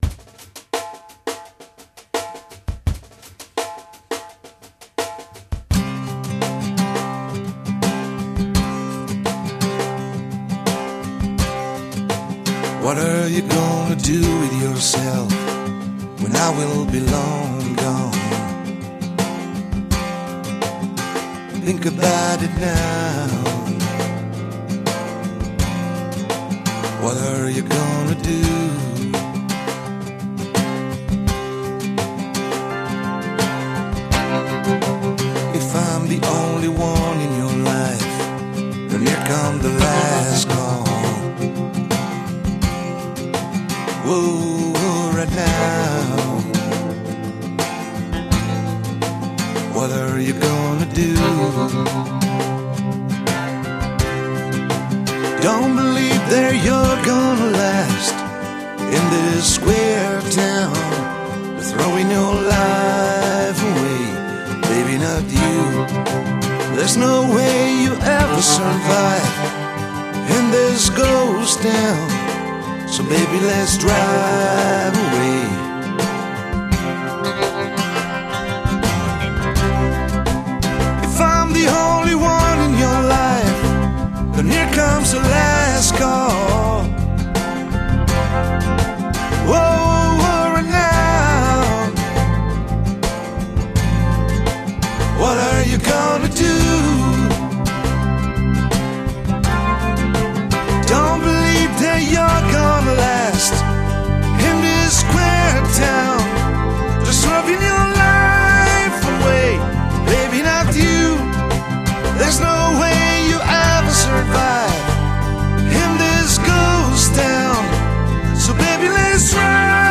Electric Guitars - Keyboards and Drums Programming
Acoustic Guitar - Bass - Vocals